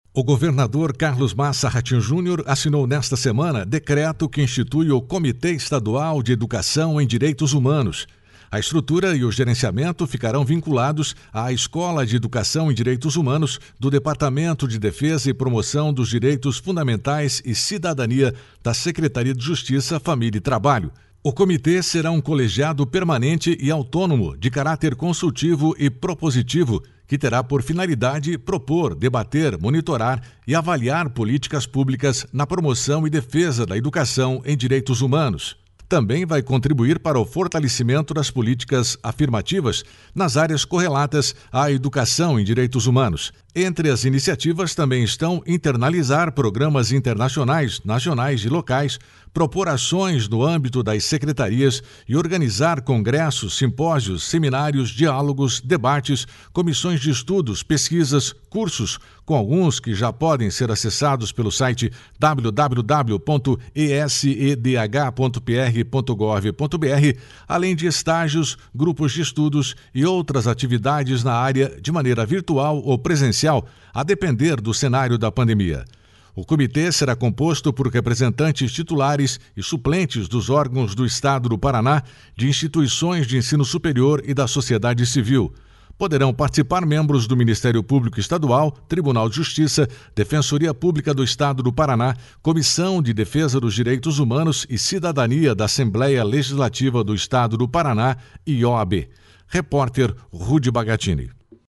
(Repórter